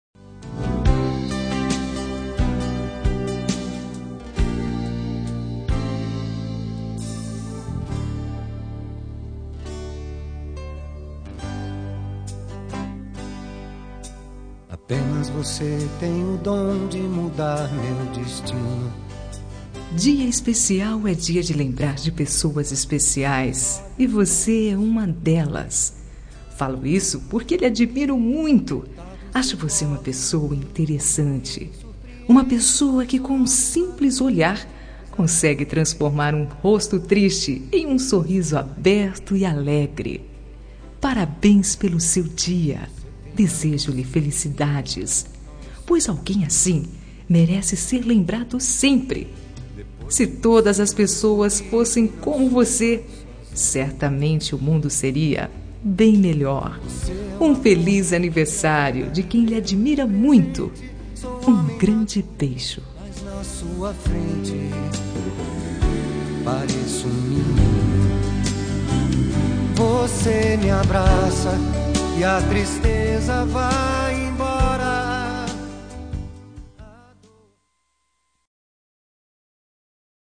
Telemensagem Aniversário de Paquera -Voz Feminina – Cód: 1237